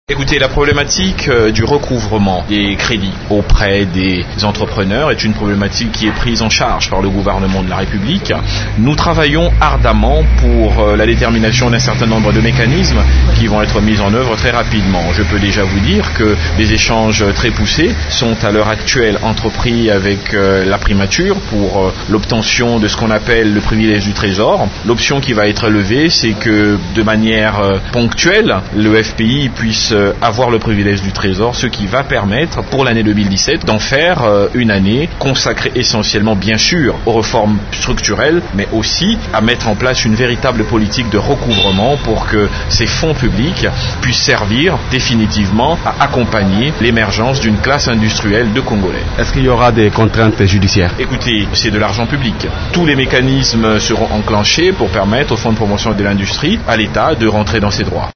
Vous pouvez écouter les explications de M. Kambinga.